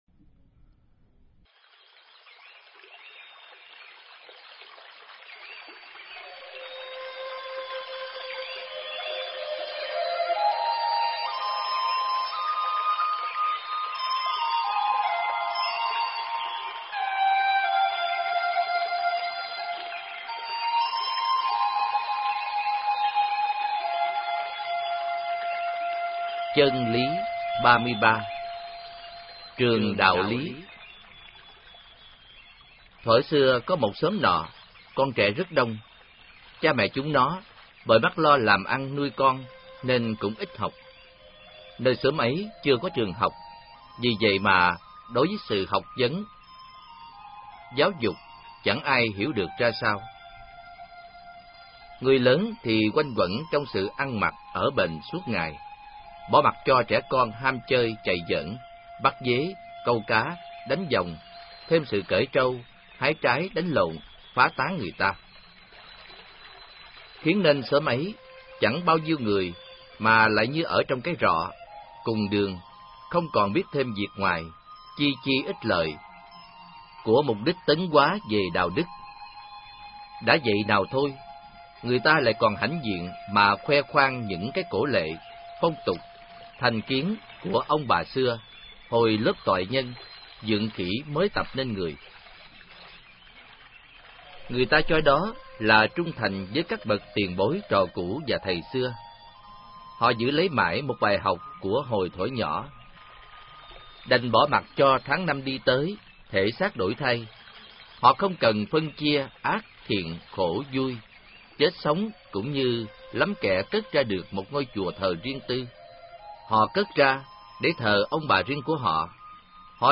Nghe sách nói chương 33. Trường Đạo Lý